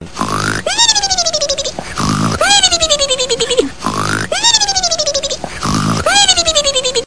Snoring-sound-HIingtone